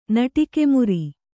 ♪ naṭike muri